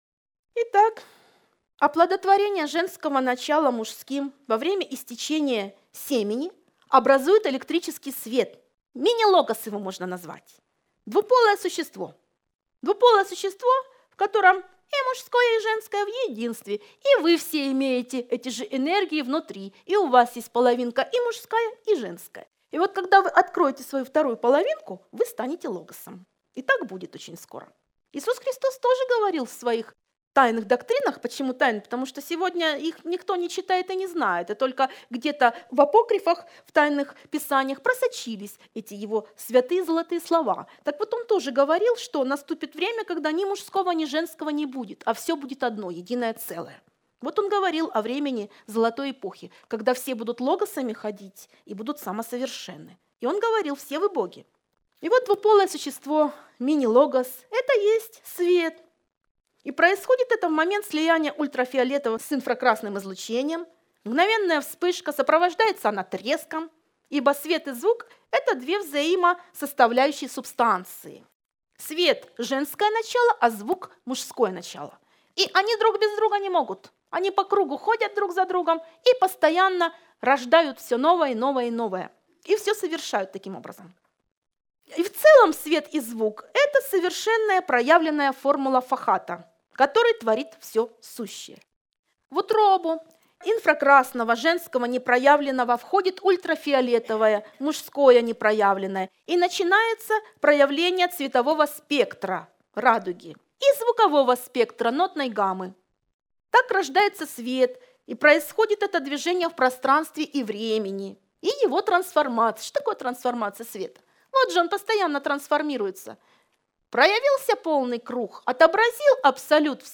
Лекция-семинар